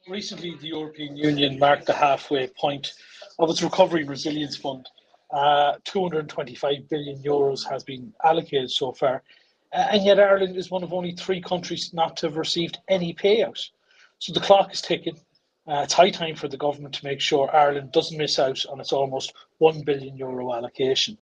MEP Chris MacManus said it is now seems increasingly likely that Ireland will not be able to actually draw down its full allocation of nearly a billion euros: